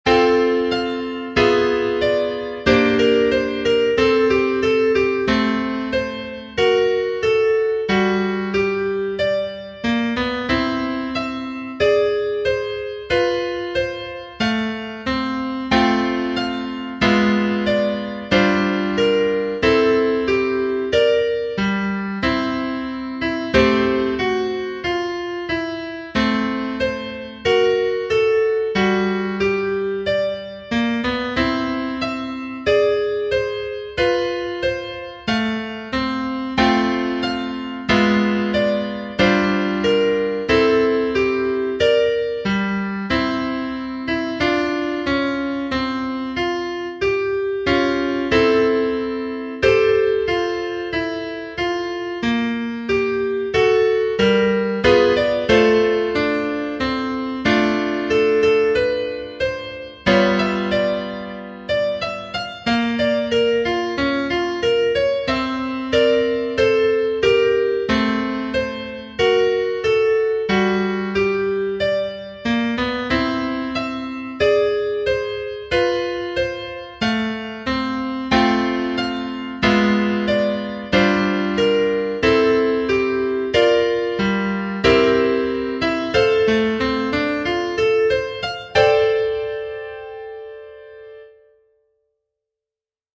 ハンドベル